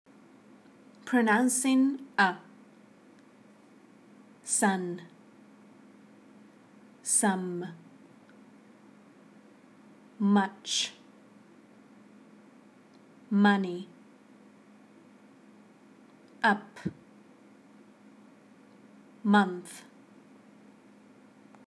All are pronounced with /ʌ/